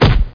thump2.mp3